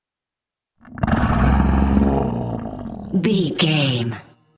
Monster snarl short close 232
Sound Effects
scary
ominous
eerie
angry